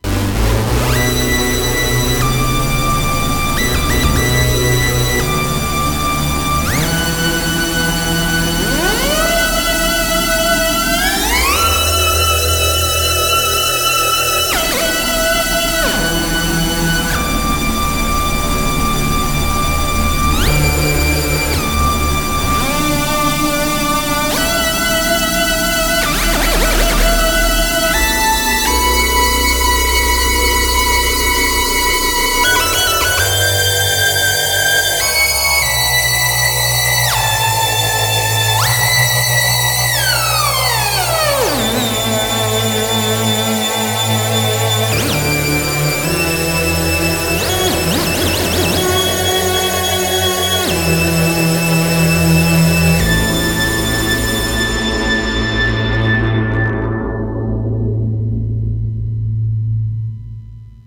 PitchADSR1-Part1 (DEMO Audio)